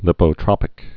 (lĭpō-trŏpĭk, -trōpĭk, līpō-)